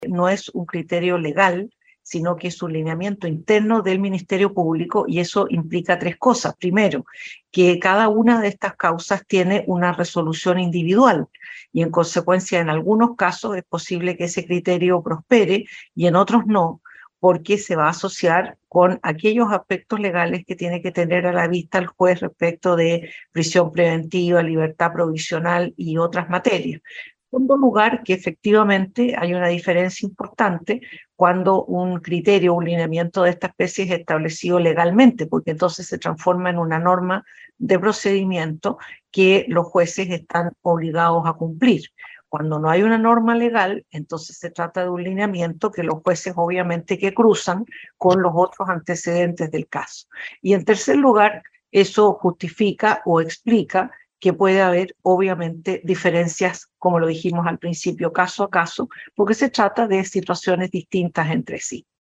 Dentro de ese mismo debate, la ministra vocera de la Corte Suprema, Ángela Vivanco, clarificó en su punto de prensa semanal que el denominado «criterio Valencia» constituye solo un documento de circulación interna, lo que no obligaría a los jueces a tener en vista el lineamiento, como sí lo haría una ley o criterio legal.